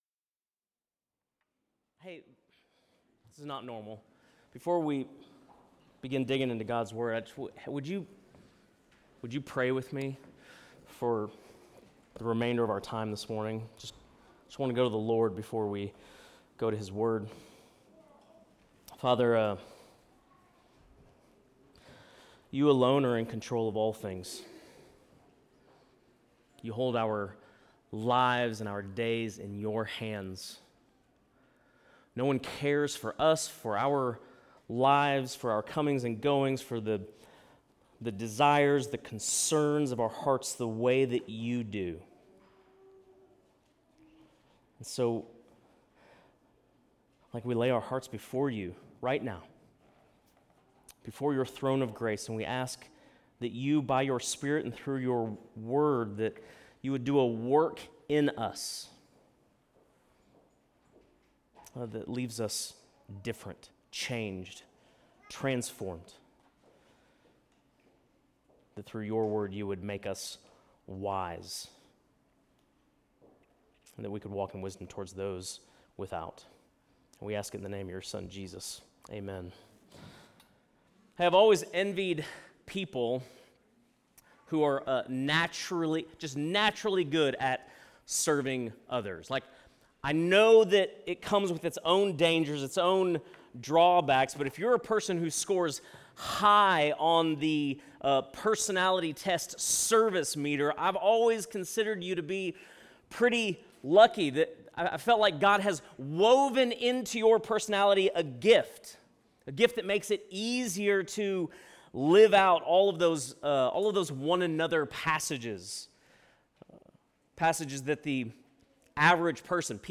Sunday Worship | Substance Church, Ashland, Ohio
Sermons